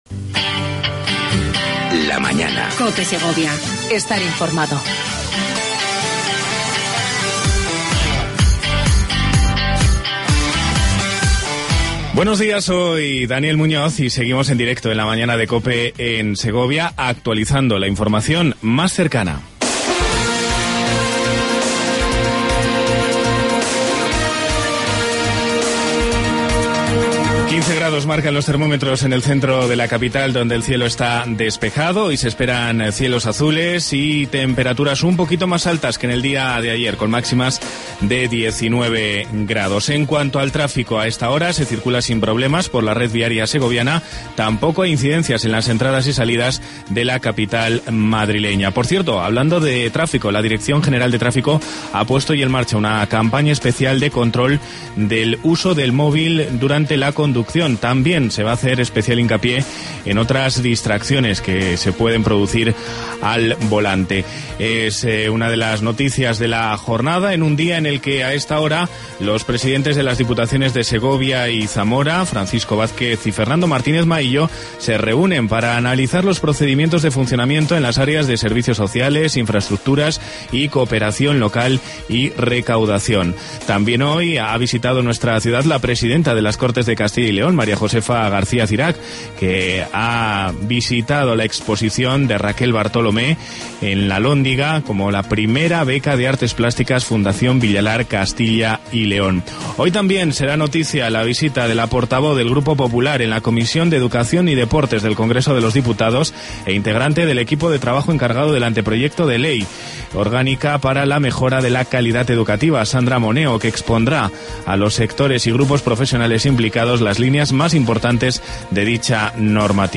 AUDIO: Entrevista con Francisco Vazquez, presidente de La Diputación de Segovia.